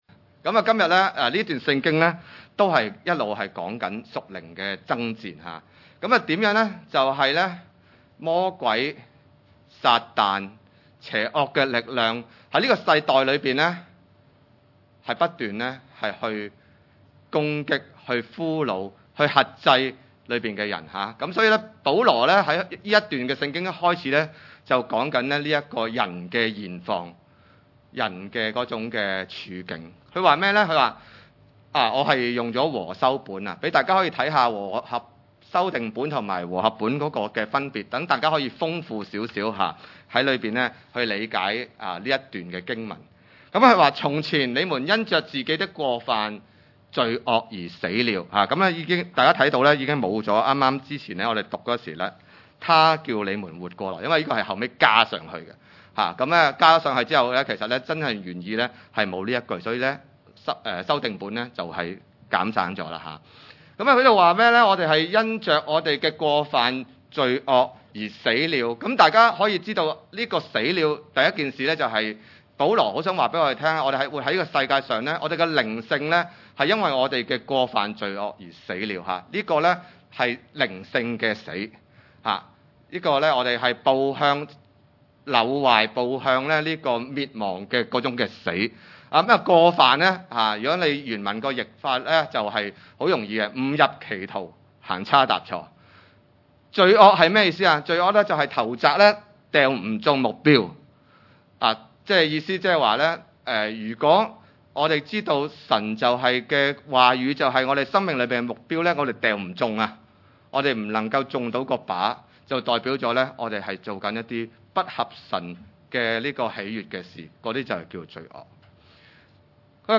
以弗所書2:1-10 崇拜類別: 主日午堂崇拜 1 你們死在過犯罪惡之中，祂叫你們活過來。